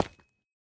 Minecraft Version Minecraft Version latest Latest Release | Latest Snapshot latest / assets / minecraft / sounds / block / calcite / step6.ogg Compare With Compare With Latest Release | Latest Snapshot
step6.ogg